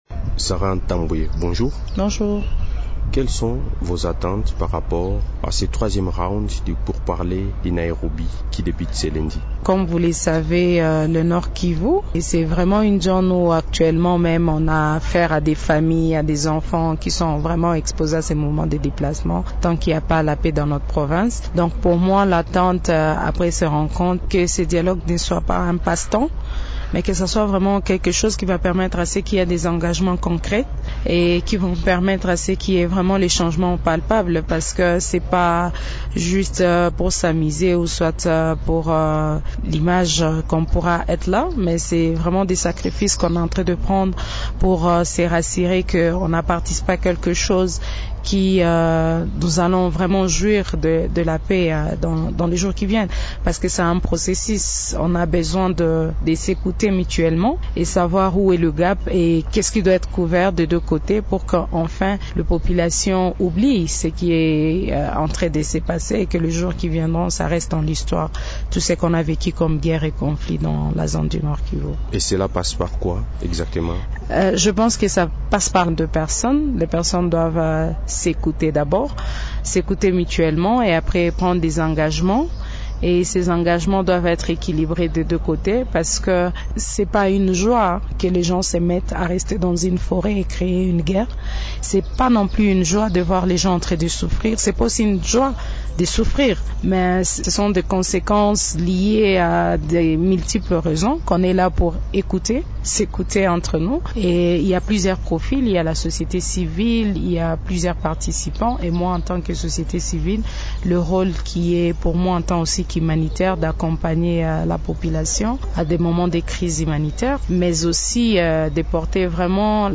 L'invité du jour, Émissions / Norbert Basengezi, ANCE, échange de voeux